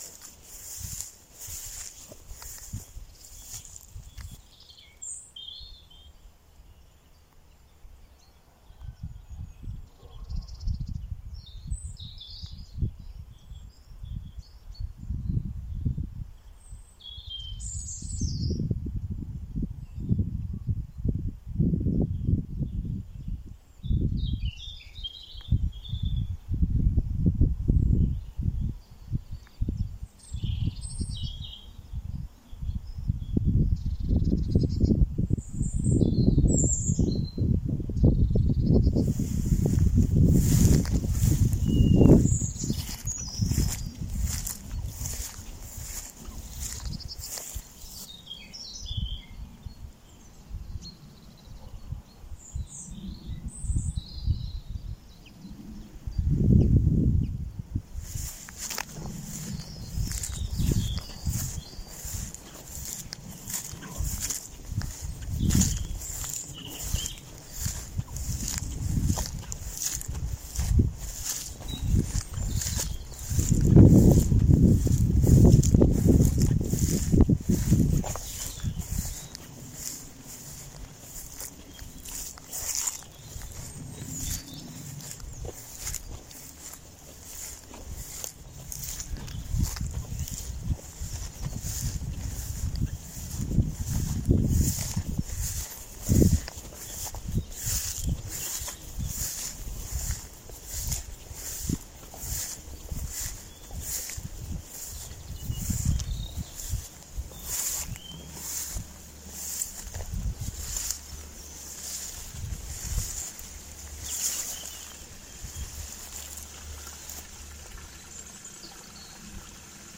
L’enregistrement n’est pas exceptionnel, on entend un peu le vent, désolé pour cette fois-ci, mais je vais m’équiper et j’espère pouvoir vous proposer bientôt des petits reportages audio.
Et voilà le plan de la balade audio :